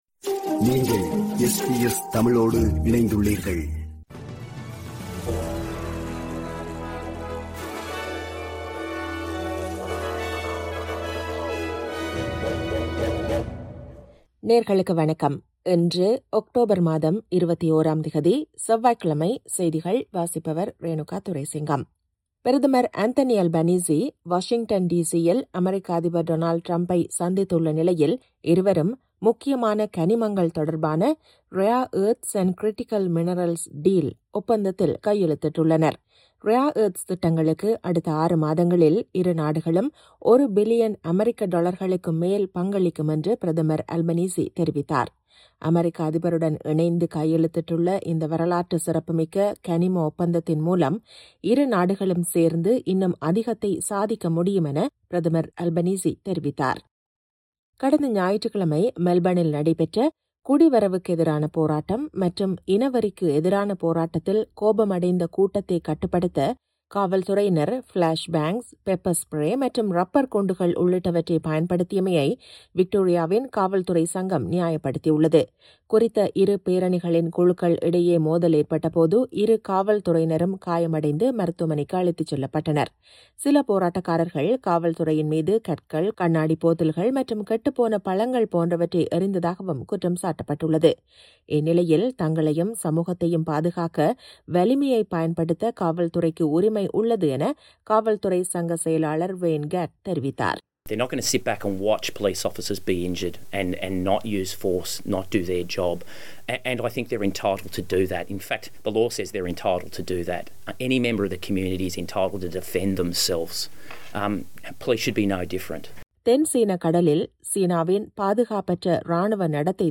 இன்றைய செய்திகள்: 21 அக்டோபர் 2025 செவ்வாய்க்கிழமை
SBS தமிழ் ஒலிபரப்பின் இன்றைய (செவ்வாய்க்கிழமை 21/10/2025) செய்திகள்.